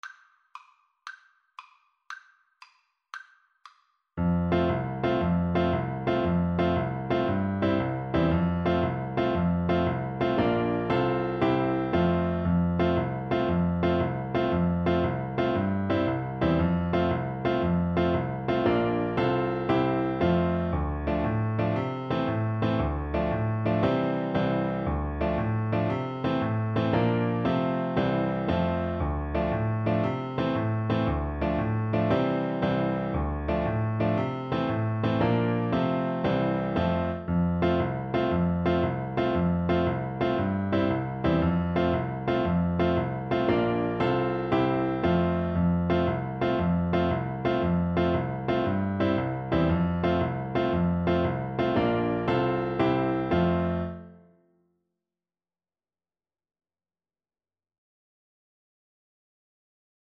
Play (or use space bar on your keyboard) Pause Music Playalong - Piano Accompaniment Playalong Band Accompaniment not yet available reset tempo print settings full screen
6/8 (View more 6/8 Music)
F major (Sounding Pitch) C major (French Horn in F) (View more F major Music for French Horn )
With energy .=c.116
Irish